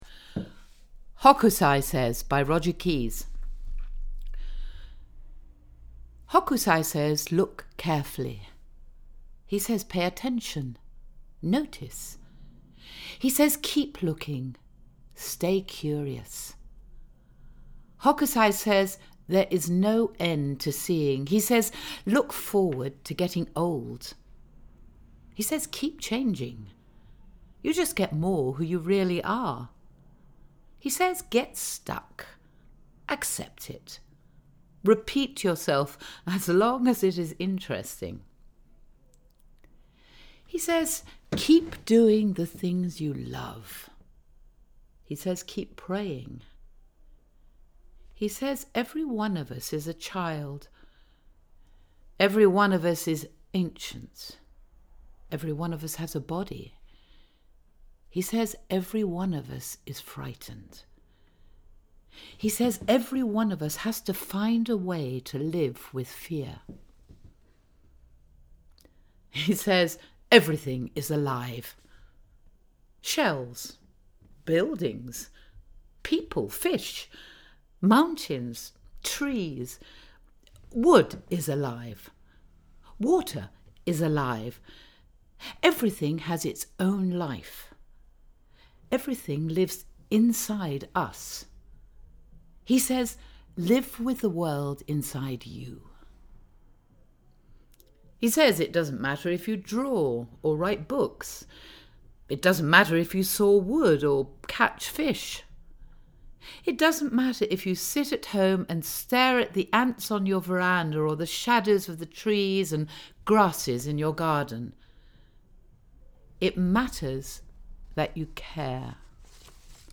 Voice Work
Example of poetry: 'Hokusai Says' by Roger Keyes